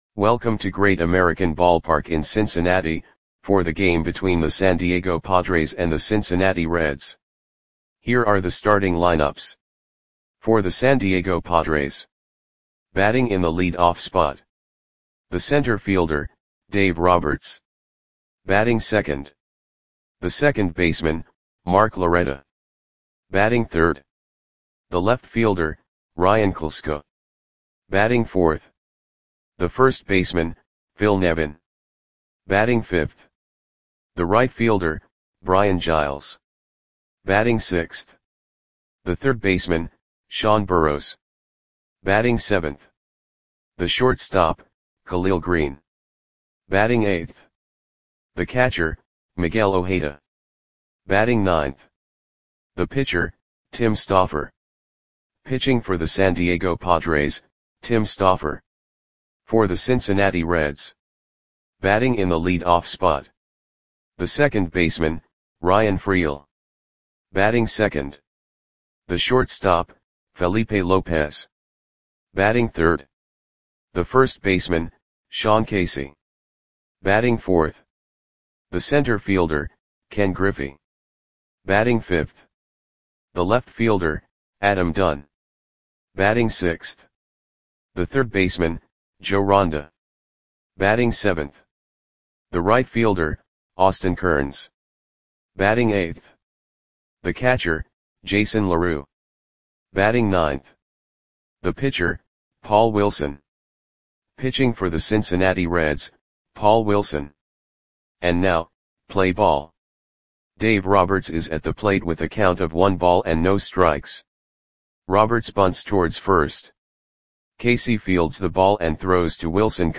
Audio Play-by-Play for Cincinnati Reds on May 11, 2005
Click the button below to listen to the audio play-by-play.